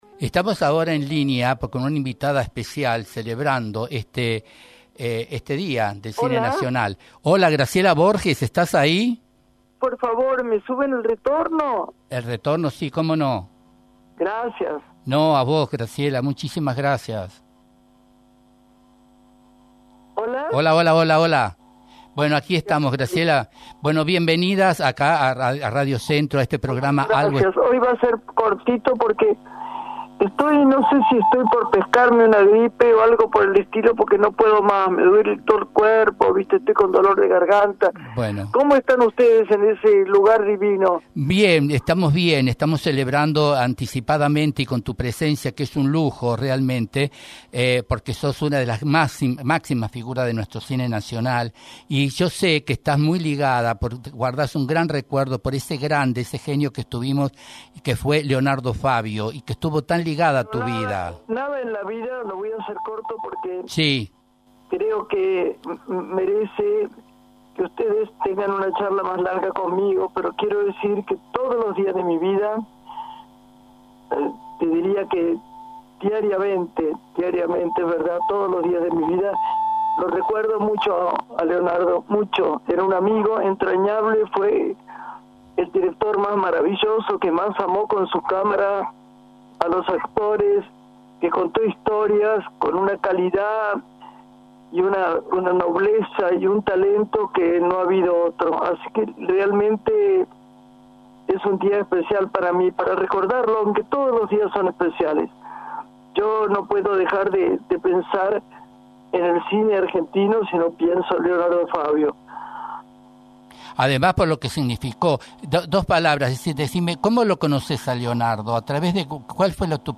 En el marco del Día del Cine Nacional, la reconocida actriz Graciela Borges fue entrevistada en el programa “Algo está sucediendo” de Radio Centro, donde recordó con emoción a su gran amigo y director Leonardo Favio, con quien compartió una de las obras más emblemáticas del cine argentino: “El dependiente”.